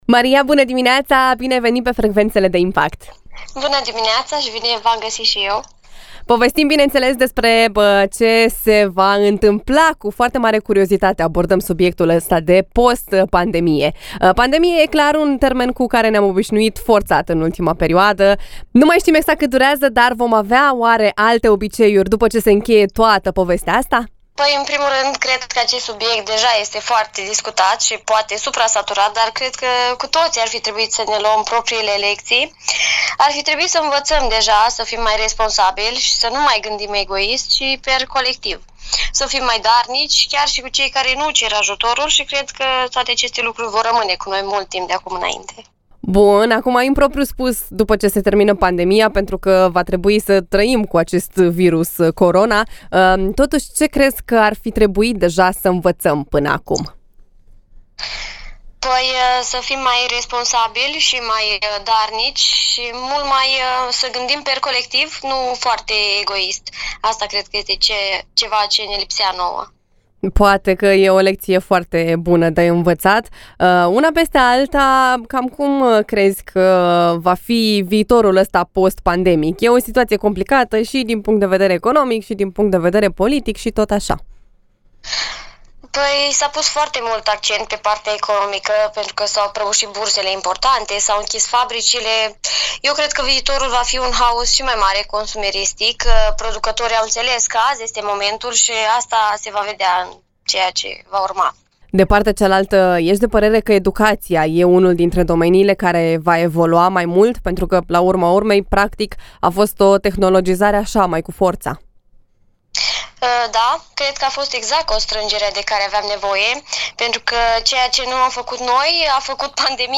Perioada post-pandemie în viziunea tinerilor – INTERVIU
Iată mai jos interviul integral: